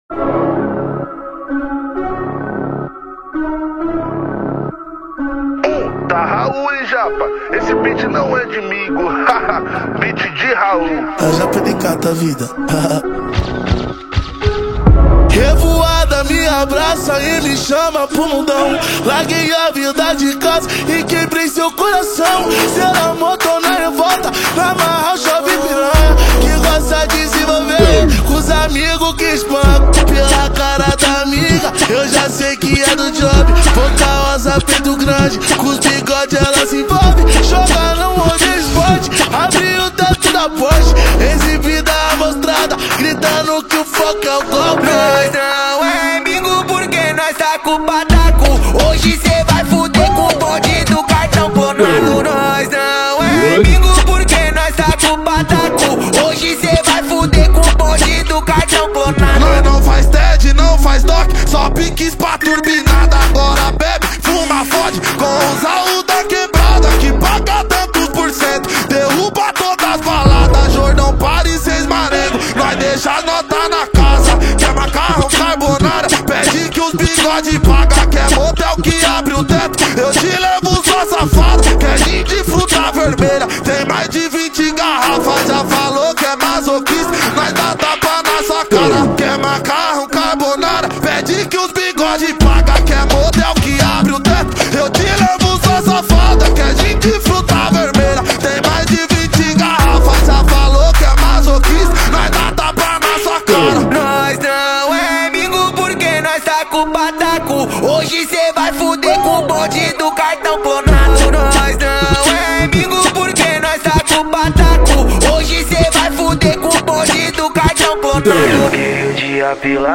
2024-11-09 18:56:50 Gênero: MPB Views